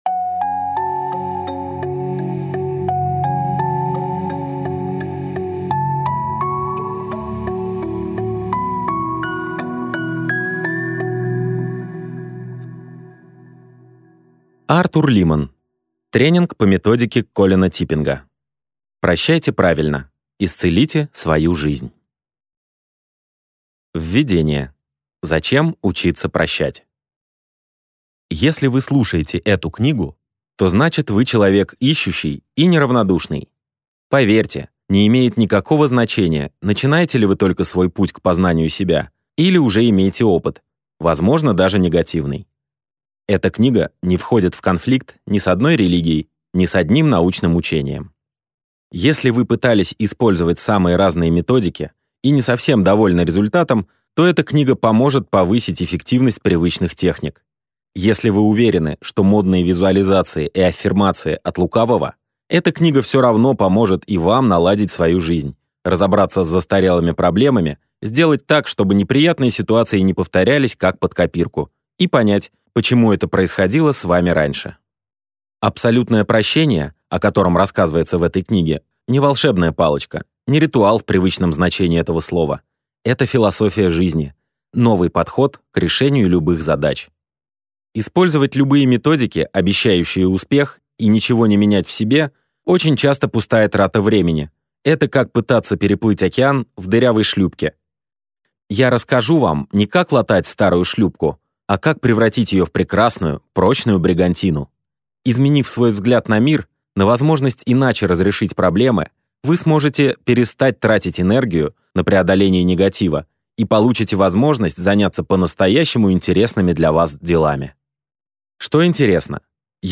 Аудиокнига Тренинг по методике Колина Типпинга. Прощайте правильно – исцелите свою жизнь!